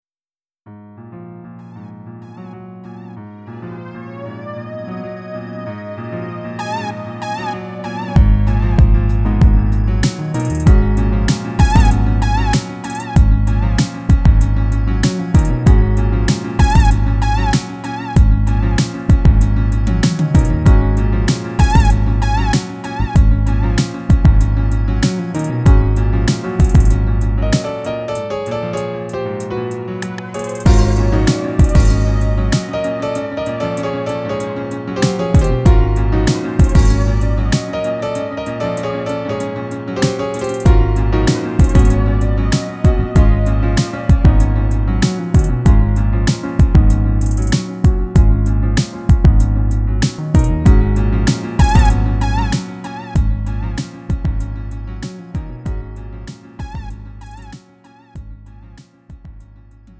음정 원키
장르 구분 Lite MR